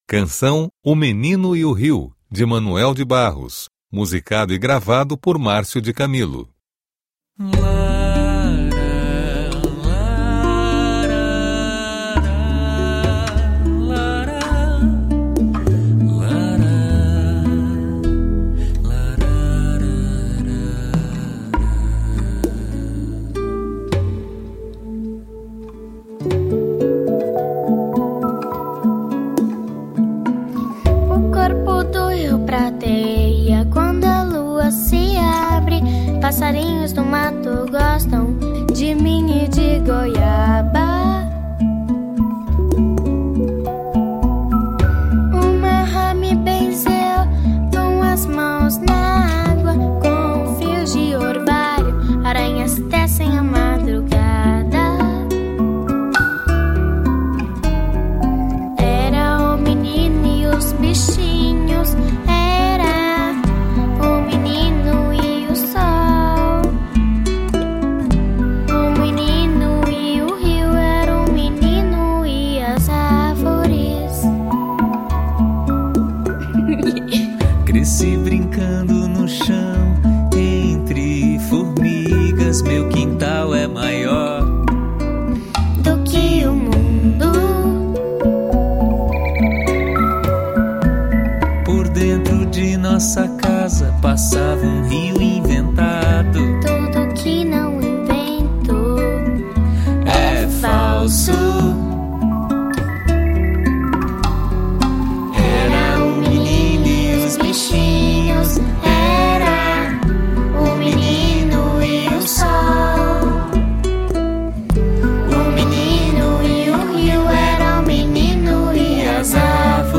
p_BUpor4_un06aut_cancao_menino.mp3